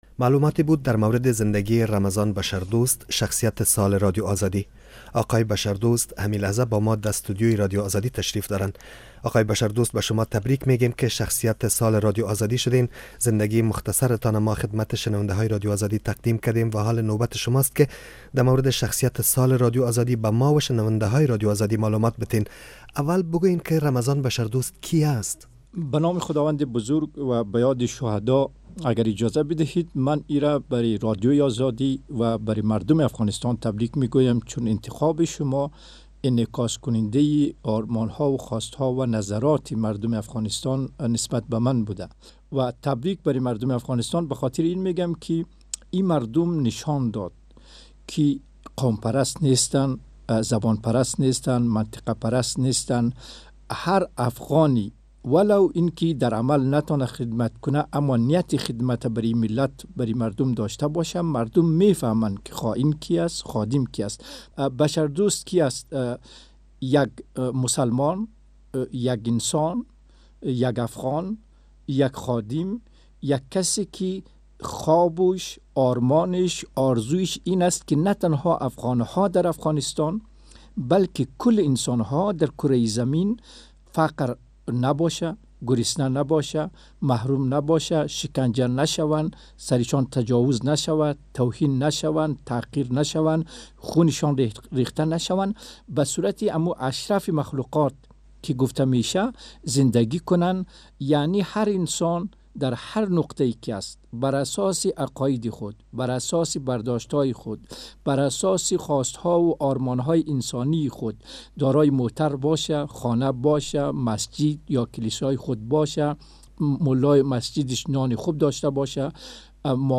مصاحبه بارمضان بشردوست